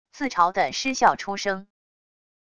自嘲的失笑出声wav音频